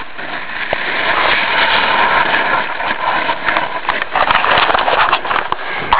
Tram Leaving Top View From Top Too Icey Hear How Icey Boarding School Underwear Tree Up Corbetts Couloir Top of Corbetts Lunch on the Hill View of Snow King
icey.wav